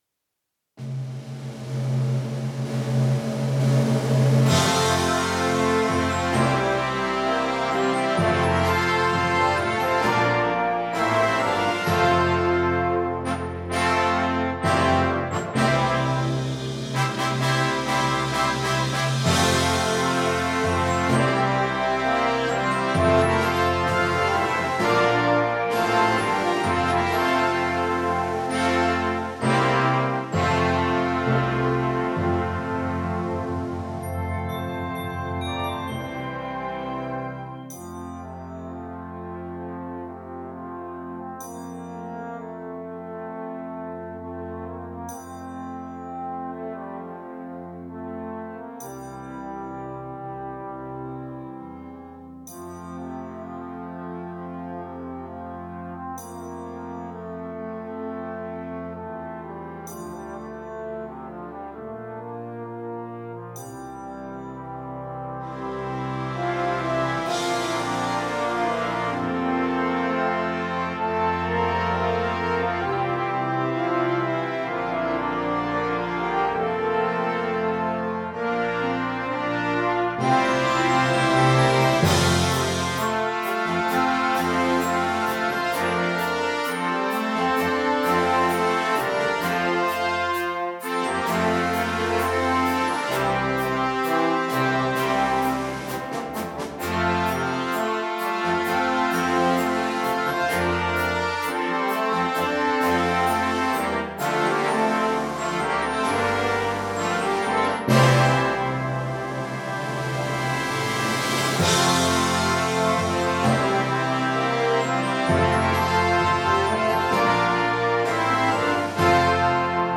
Gattung: Eröffnungswerk für Blasorchester
Besetzung: Blasorchester